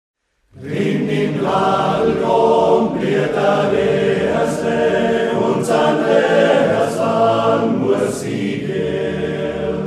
Aufgenommen im Oktober 2009 in der Volksschule